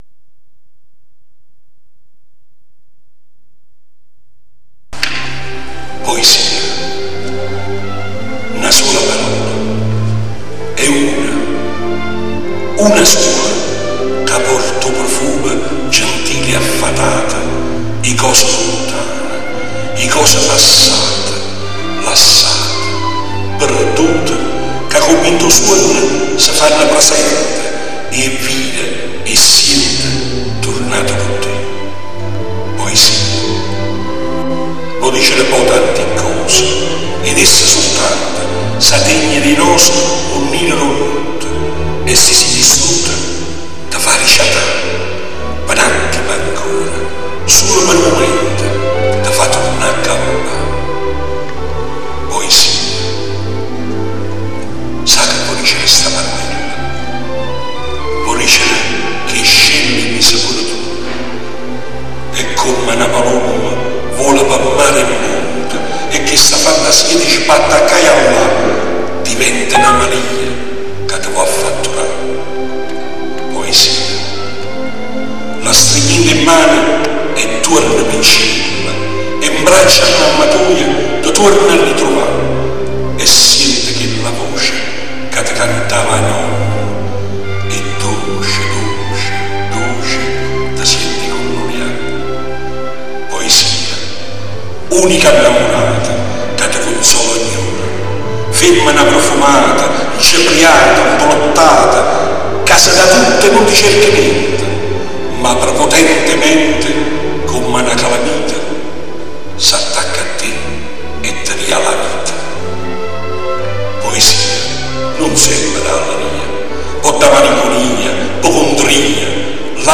clic sul fiore per ascoltare la poesia recitata dall'Autore